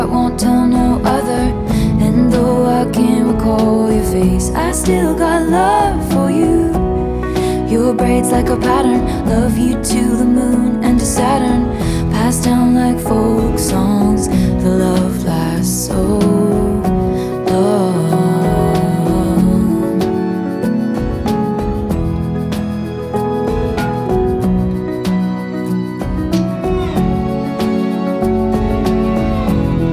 • Alternative
folk song